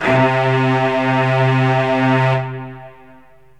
STRINGS 0003.wav